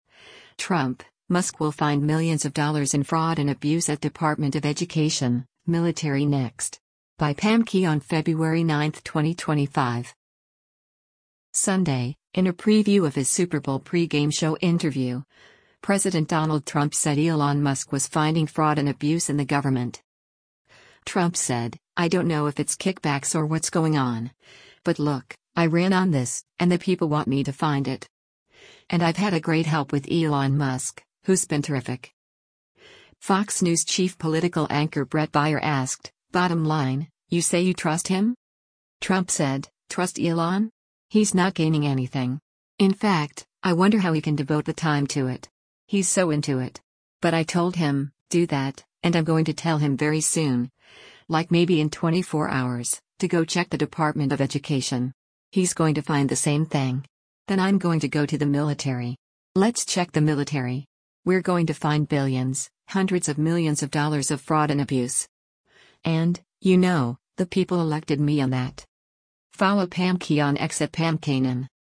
Sunday, in a preview of his Super Bowl pregame show interview, President Donald Trump said Elon Musk was finding fraud and abuse in the government.